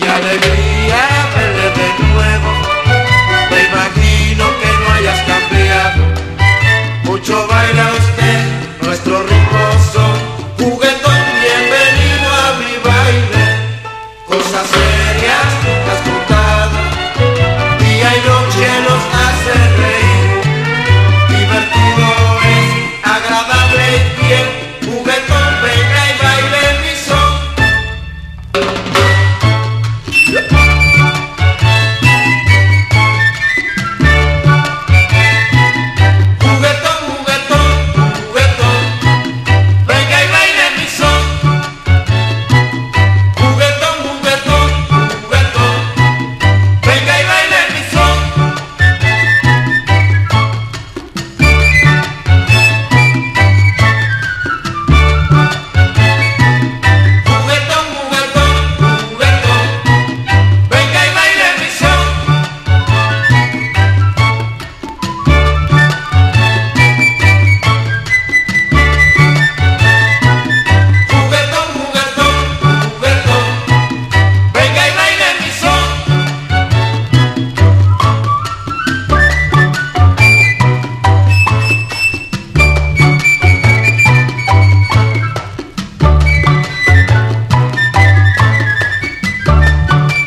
REGGAE / SKA/ROCKSTEADY / NEO SKA
ドイツはヴィースバーデンを拠点とする10人組ネオスカ・バンド！